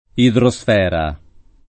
[ idro S f $ ra ]